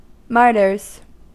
Ääntäminen
Ääntäminen US Haettu sana löytyi näillä lähdekielillä: englanti Käännöksiä ei löytynyt valitulle kohdekielelle. Martyrs on sanan martyr monikko.